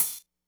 hihat.wav